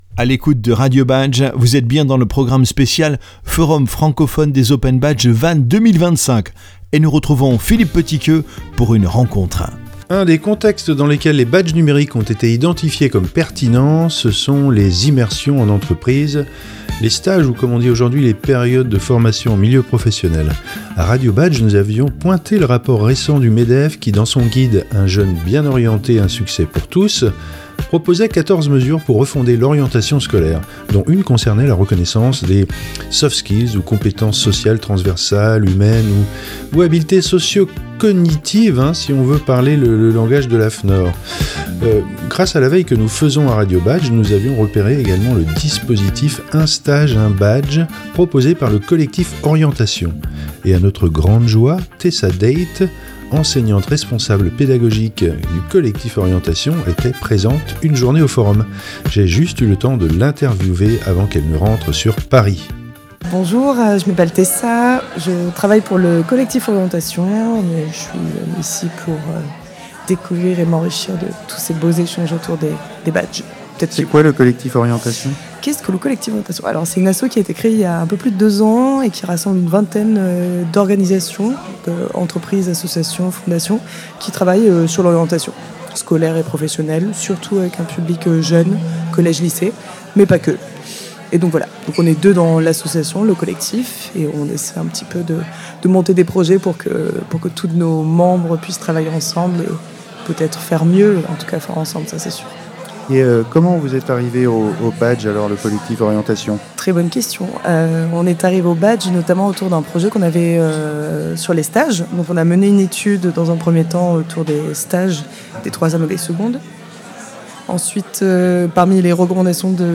Nous l'avons donc interviewée pour qu'elle nous présente le dispositif 1 stage, 1 badge.